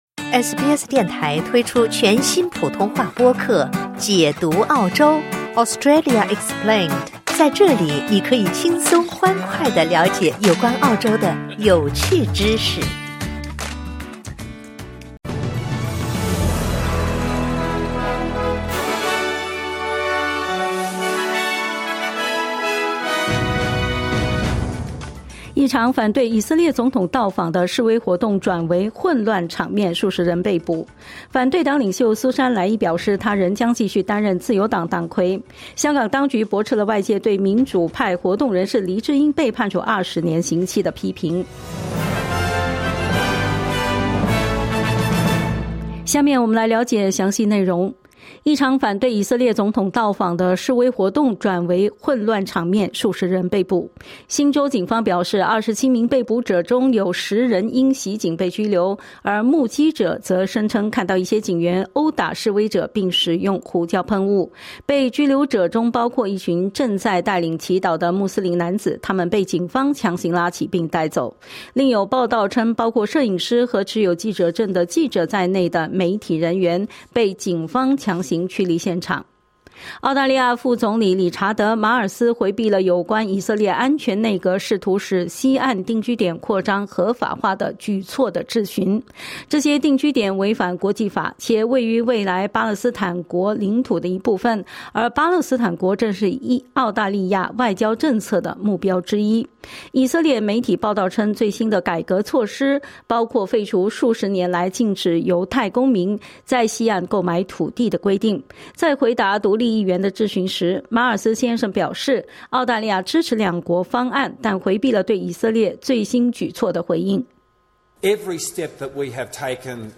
【SBS早新闻】香港当局驳斥对民主活动人士黎智英被判处20年的批评